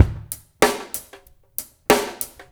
ABO DRUMS2-R.wav